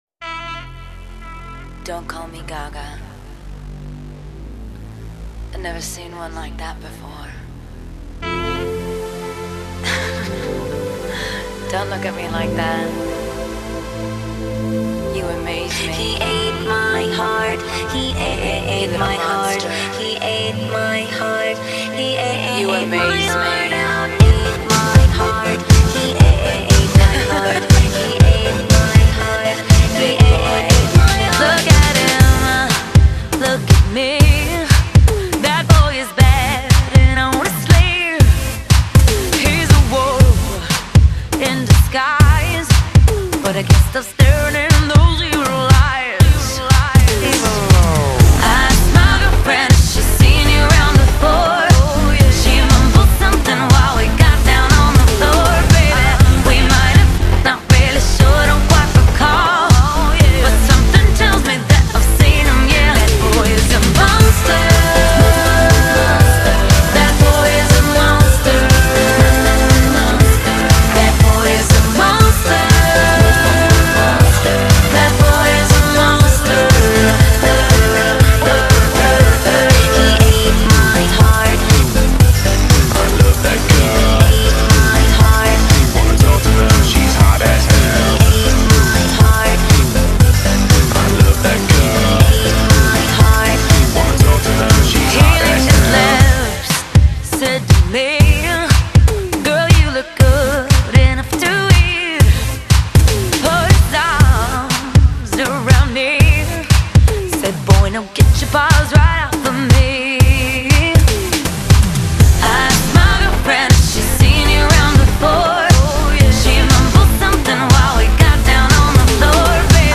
зарубежная эстрада